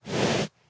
breathe1.ogg